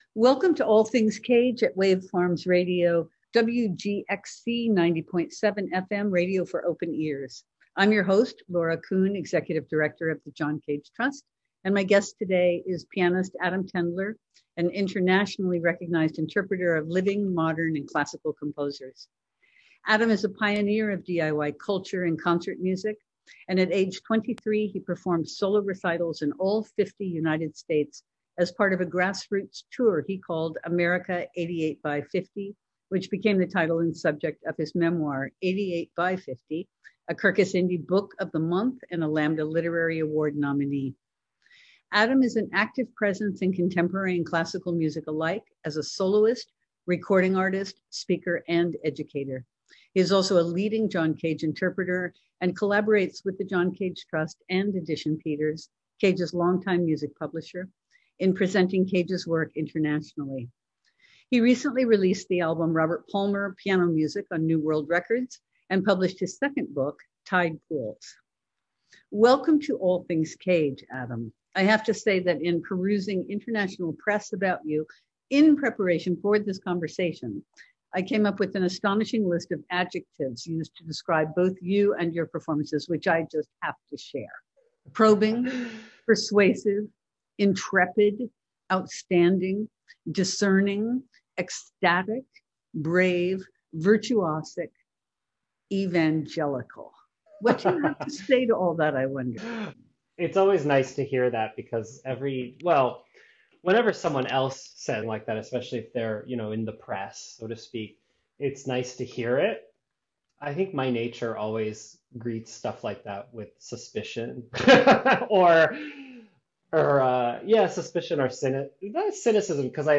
All Things Cage is a weekly program featuring conversations